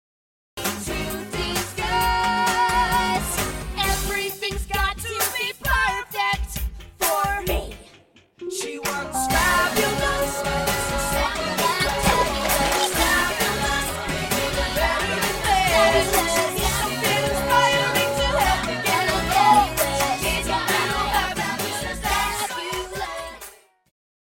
Ignore the pool splash god knows why thats in the audio..